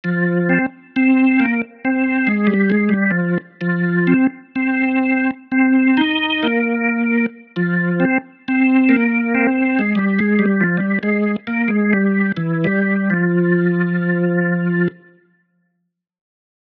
Organy Hammonda
Organy Hammonda mają bardzo charakterystyczne miękkie, wibrujące brzmienie.
Dźwięki instrumentów są brzmieniem orientacyjnym, wygenerowanym w programach:
Kontakt Native Instruments (głównie Factory Library oraz inne biblioteki) oraz Garritan (Aria Player).
Organy-Hammonda.mp3